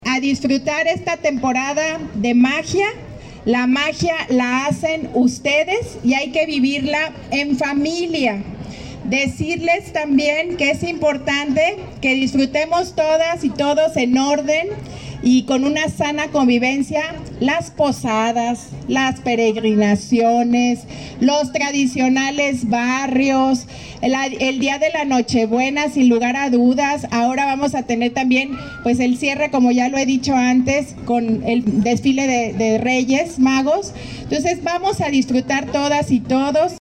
AudioBoletines
Lorena Alfaro García, presidenta municipal